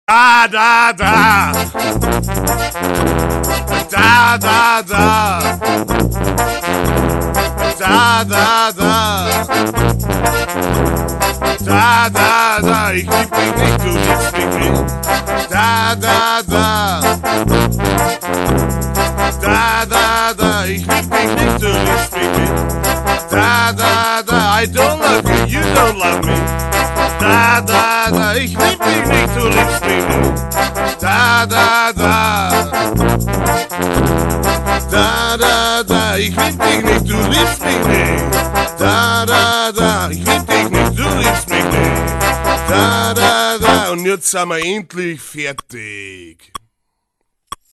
C大调